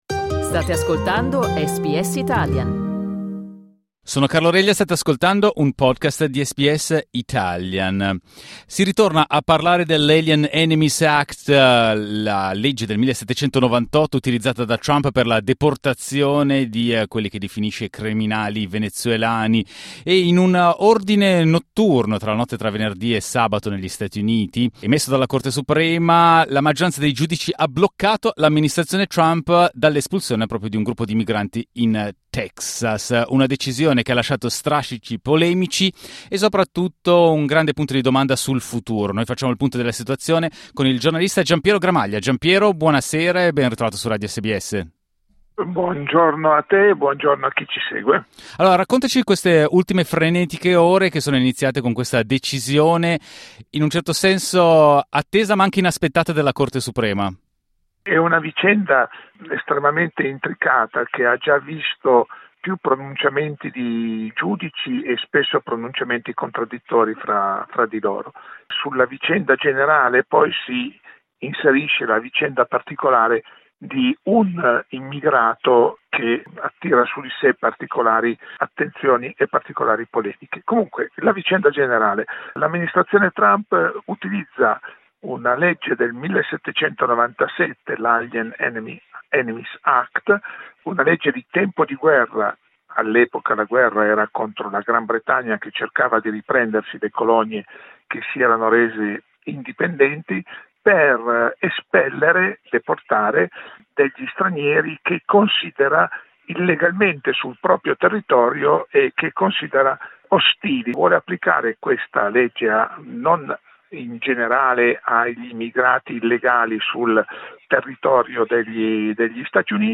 Ascolta l'analisi del giornalista